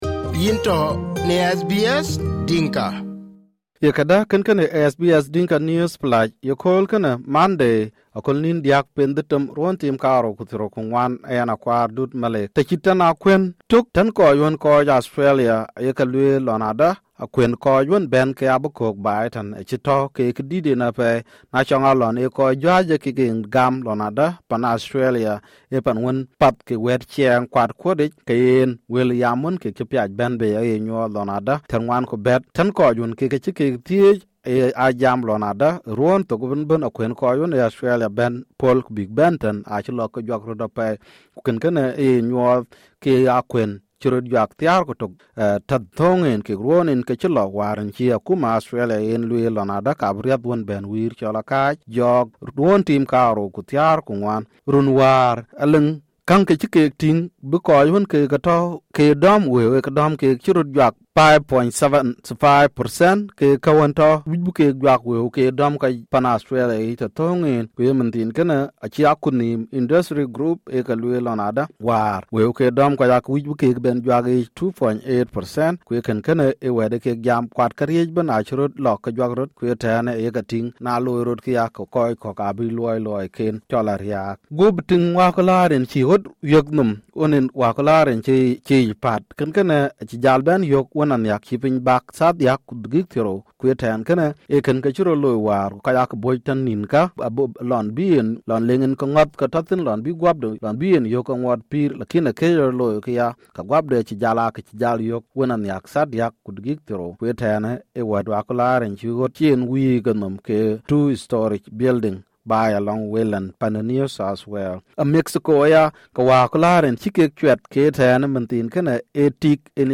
SBS Dinka News Flash for Monday 3 June 2024